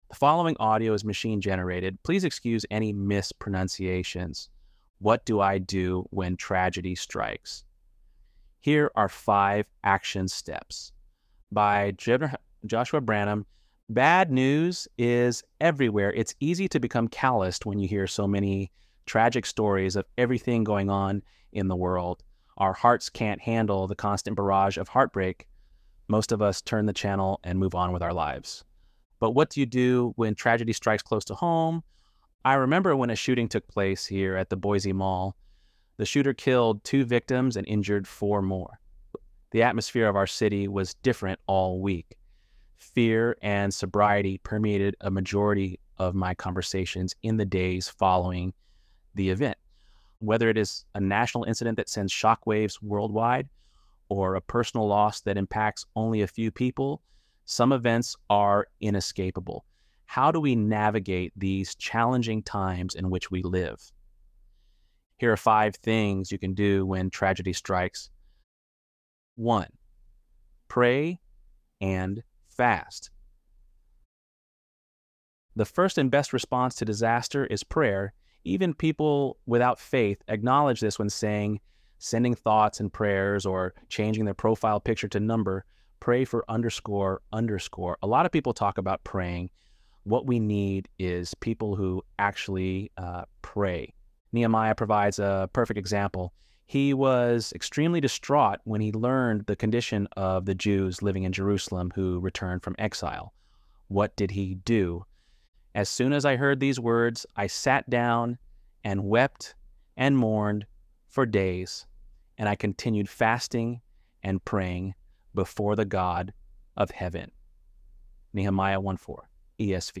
ElevenLabs_Untitled_Project-6.mp3